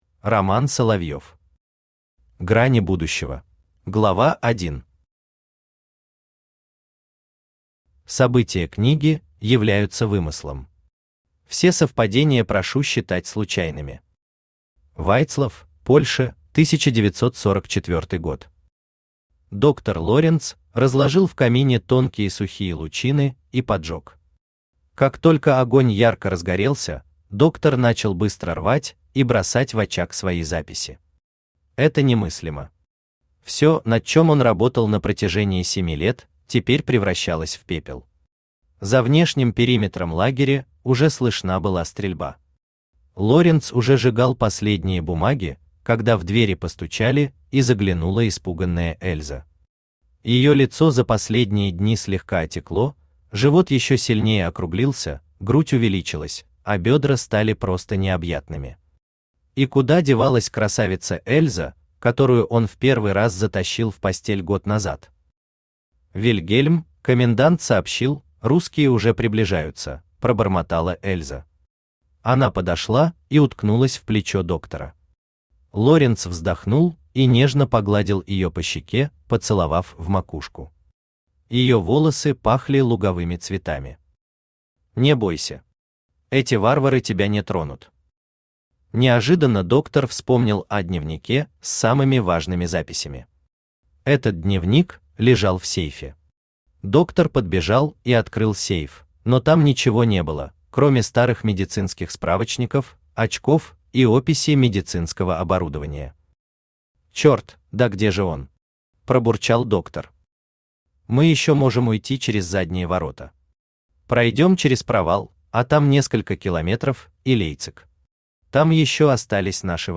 Аудиокнига Грани будущего | Библиотека аудиокниг
Aудиокнига Грани будущего Автор Роман Соловьев Читает аудиокнигу Авточтец ЛитРес.